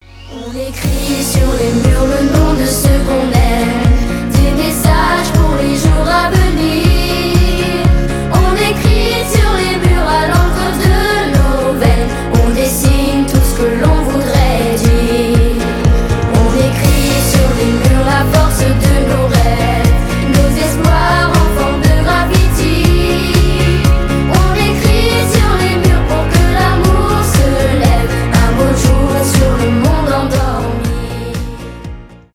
детский голос , хор
поп